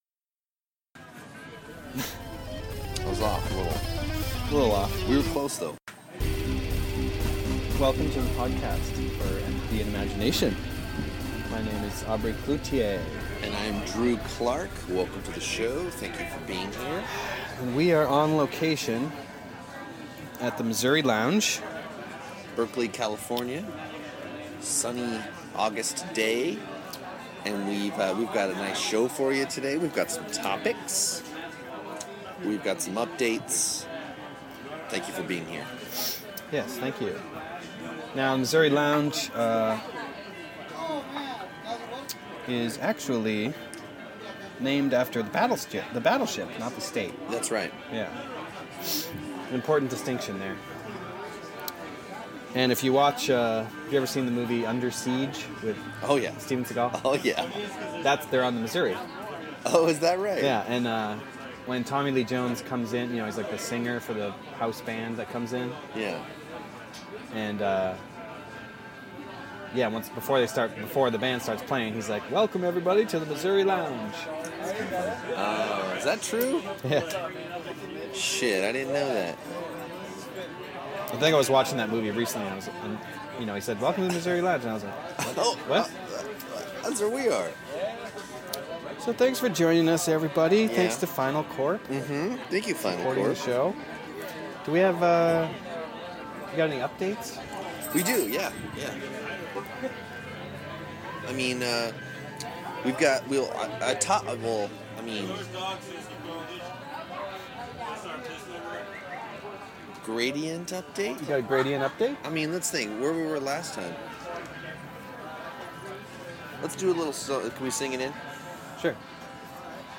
Download this episode Special Miz-cast episode, live (and on tape) from the Missouri Lounge in Berkeley, CA. This week